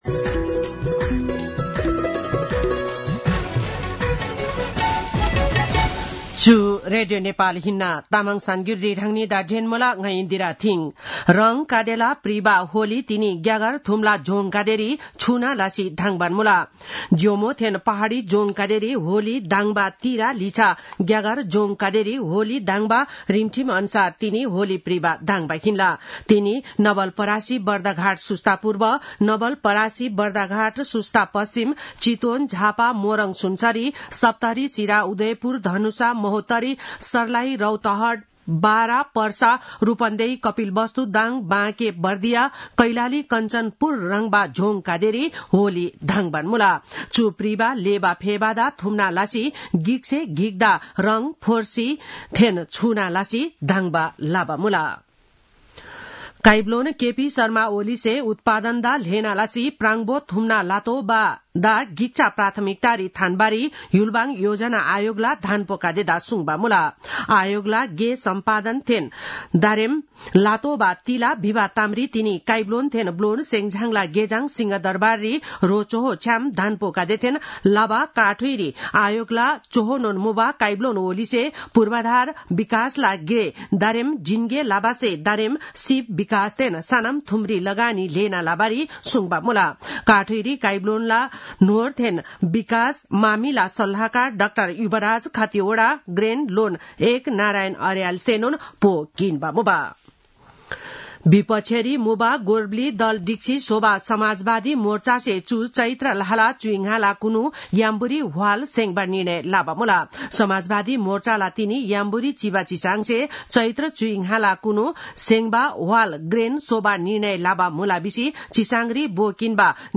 तामाङ भाषाको समाचार : १ चैत , २०८१